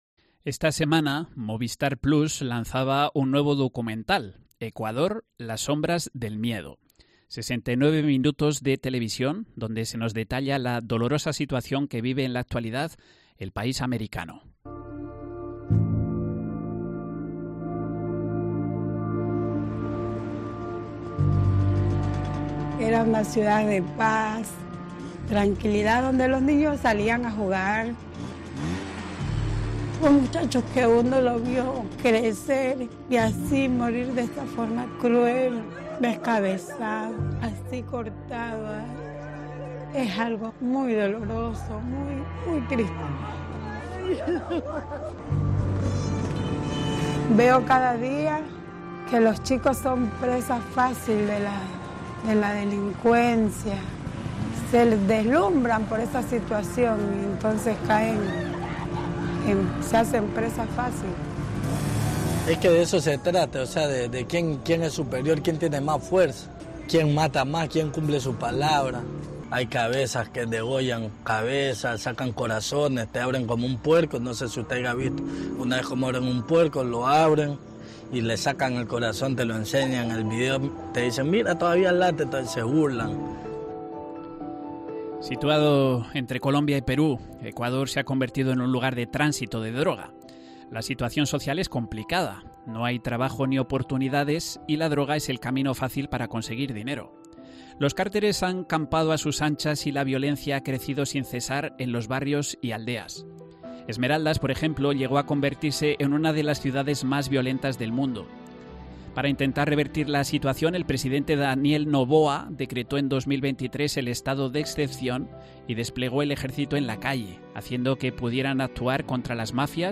Sacerdote burgalés en Ecuador